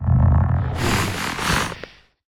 sniff_4.ogg